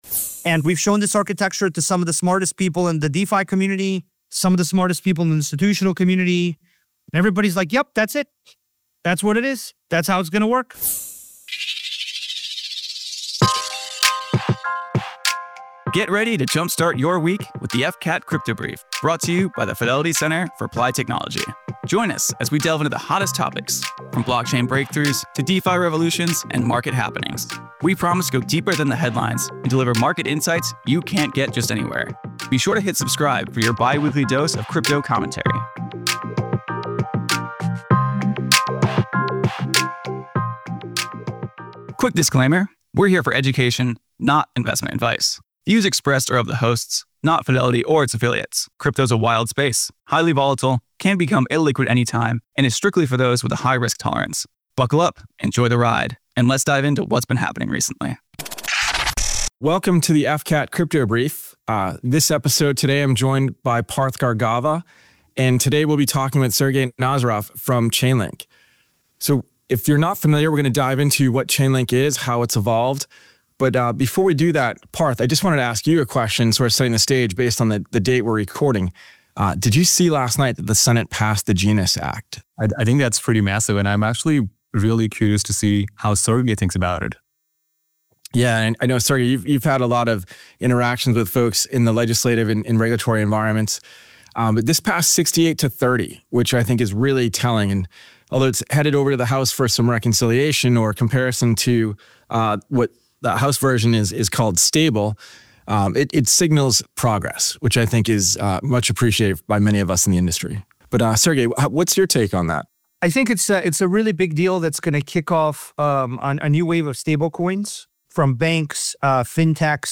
Linking TradFi & DeFi: a Conversation with Sergey Nazarov
Listen in as the hosts are joined by Sergey Nazarov, co-founder of Chainlink, to discuss his work and vision for the future of blockchain. Learn about on-chain security, permissionless innovation, the role of oracles, and how TradFi and DeFi stakeholders can work together to build better networks.